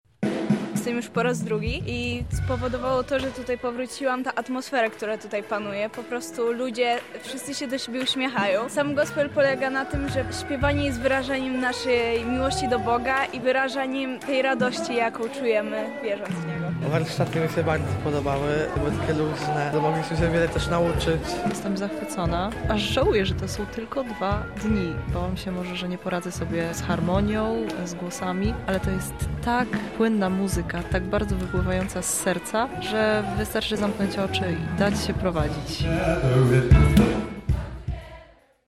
Przez 4 dni w Chatce Żaka królowała muzyka gospel.
Uczestnicy byli pod wrażeniem festiwalu.
gospel